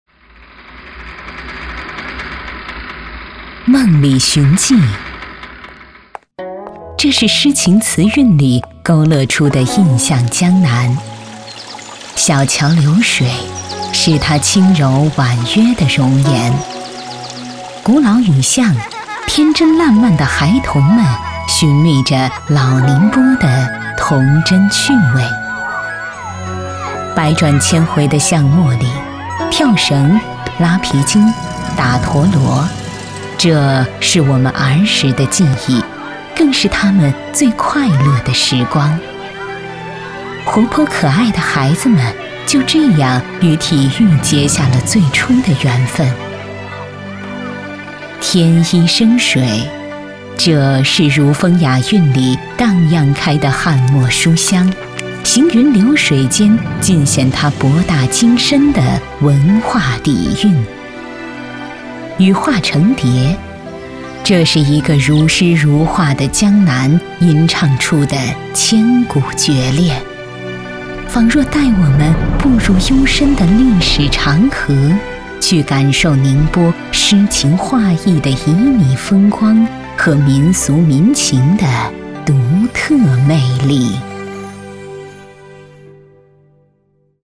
女31号-城市宣传片配音-娓娓讲述-宁波风光